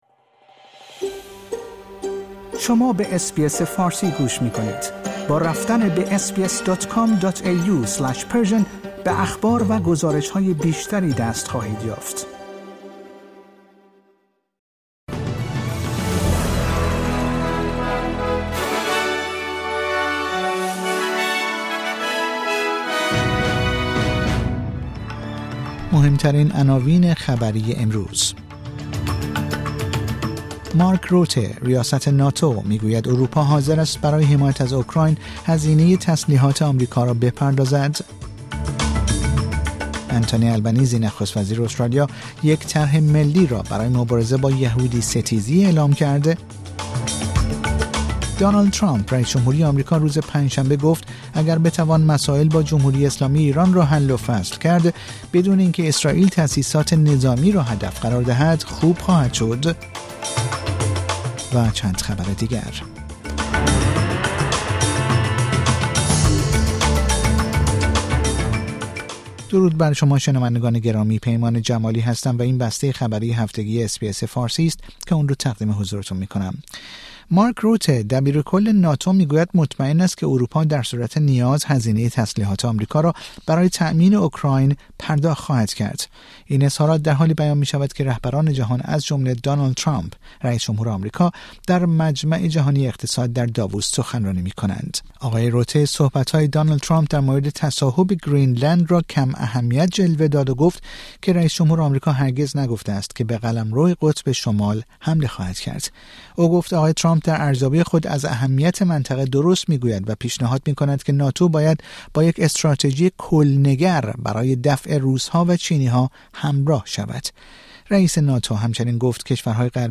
در این پادکست خبری مهمترین اخبار استرالیا، جهان و ایران در یک هفته منتهی به شنبه ۲۵ ژانویه ۲۰۲۵ ارائه شده است.